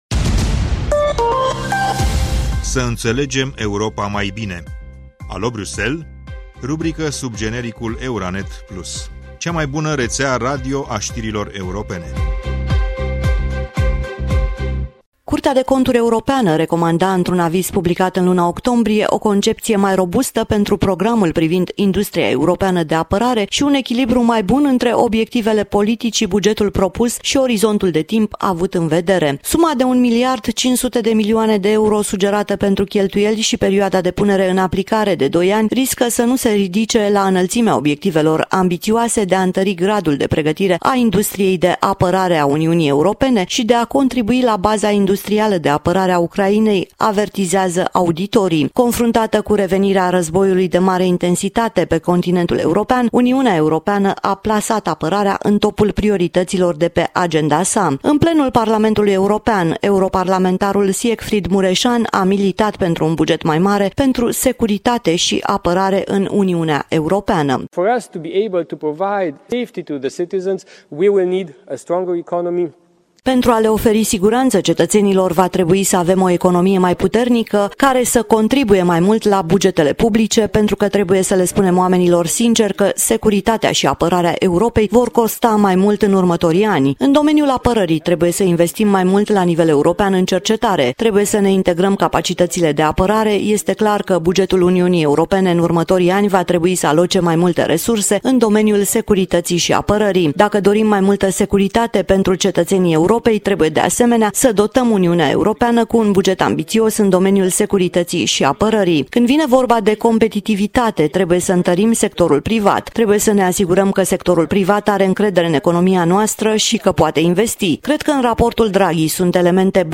În plenul Parlamentului European, europarlamentarul Siegfried Mureșan , a militat pentru un buget mai mare pentru securitate și apărare în UE.